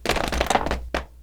wood_splinters.wav